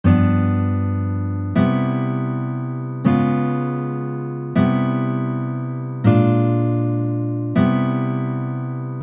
Let’s say our song needs to end on C Maj 7, but you need a more or less usable ending.
V7 -> IMaj7